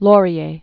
(lôrē-ā, lŏr-), Sir Wilfrid 1841-1919.